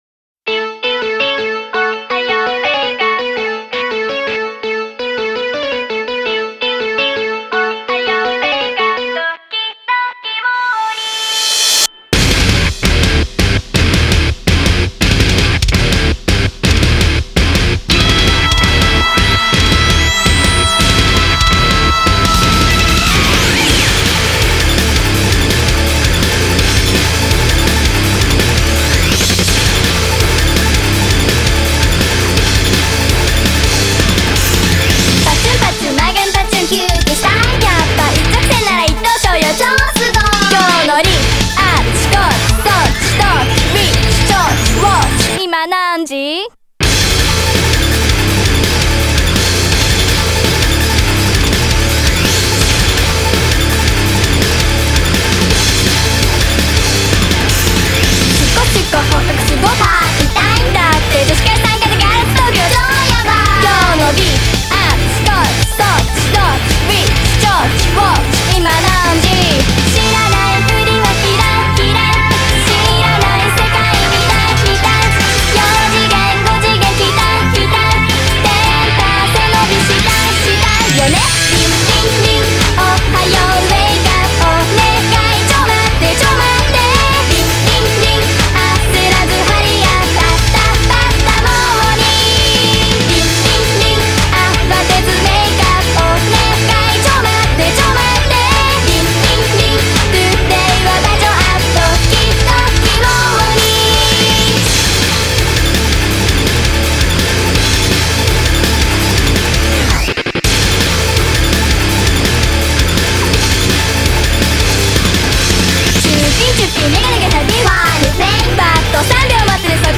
The most kawaii metal song I’ve ever heard